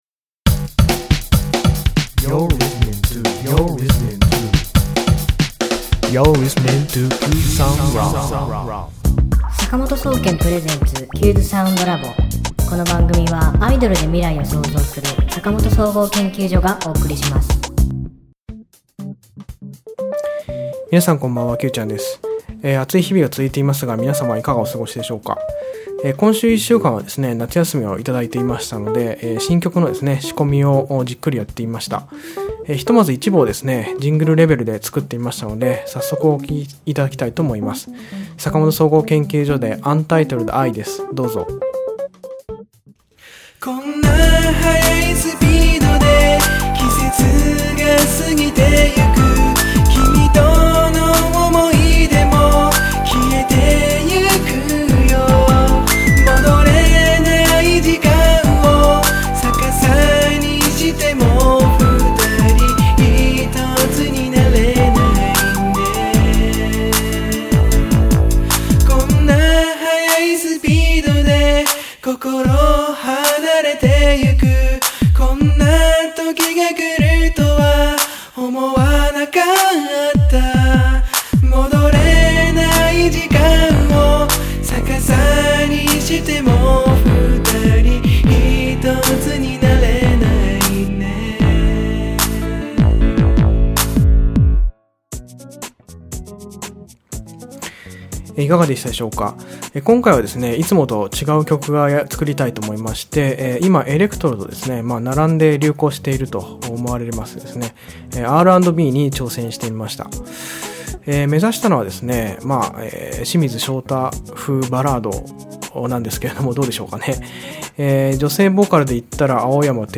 今週のテーマ：清水翔太風！？Ｒ＆Ｂのバラードを作ってみました 「いつもと違う坂本総合研究所を」ということでＲ＆Ｂに挑戦してみましたが、やっぱりテイストはいつもと一緒！？